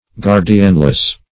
Guardianless \Guard"i*an*less\, a. Without a guardian.